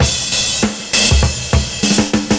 100CYMB08.wav